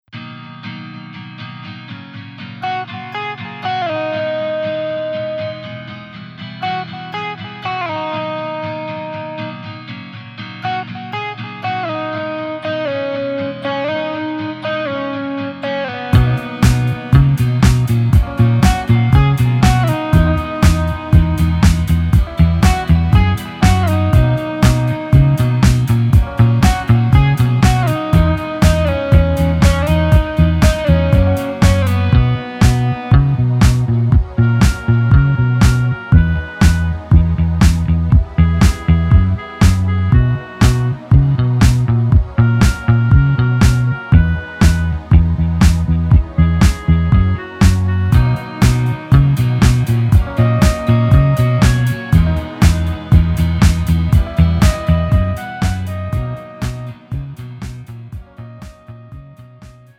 음정 -1키
장르 pop 구분 Pro MR